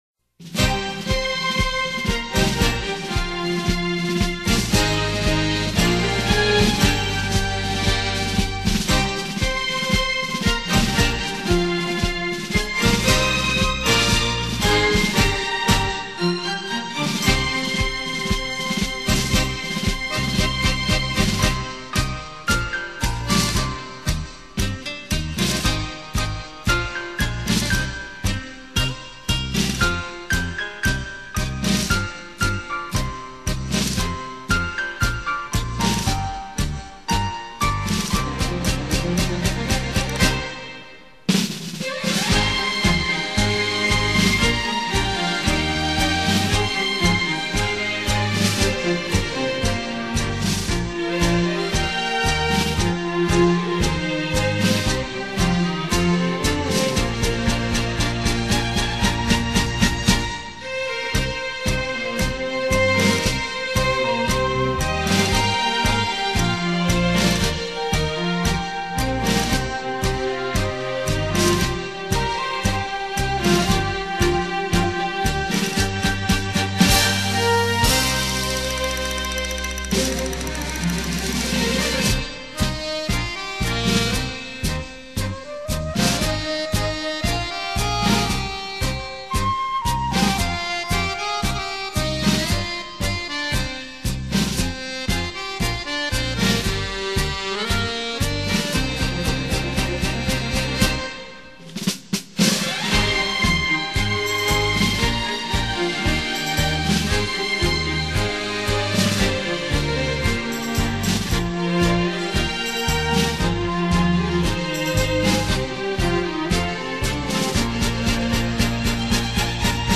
玫瑰探戈 生活情趣